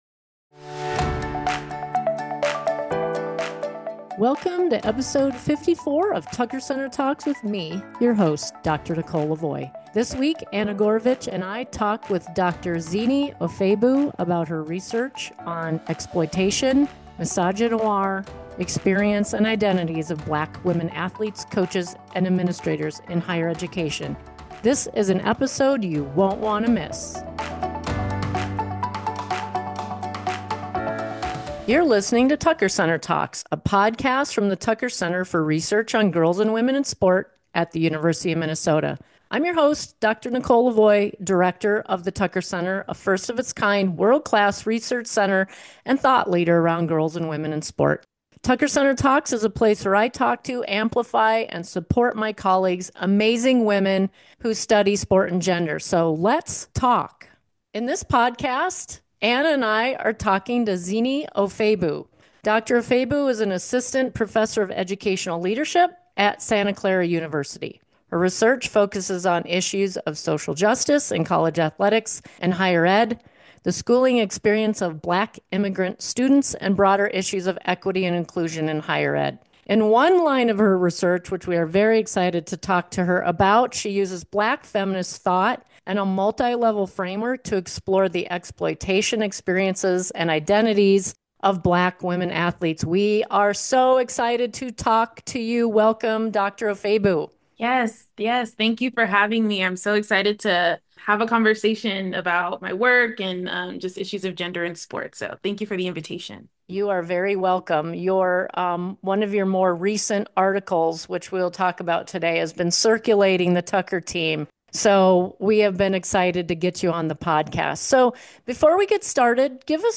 Don’t miss this compelling discussion!